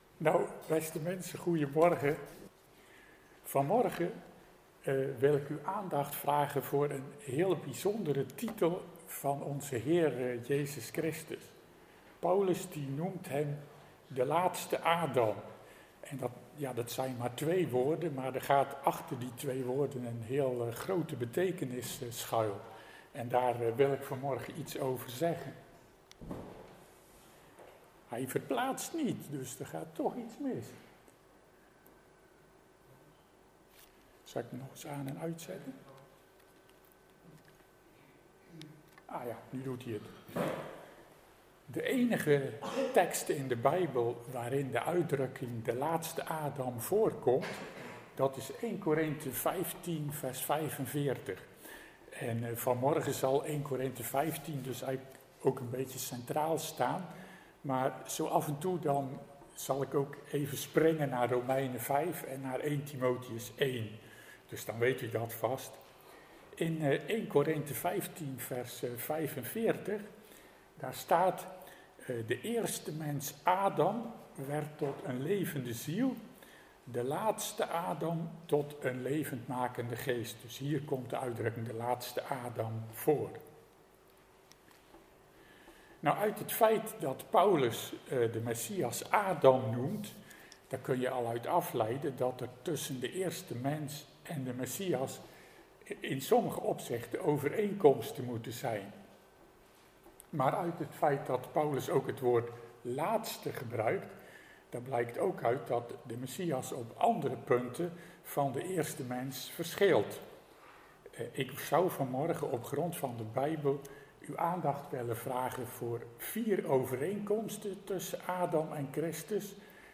Hieronder vindt u de geluidsopnames van de diensten van seizoen 2021-2022.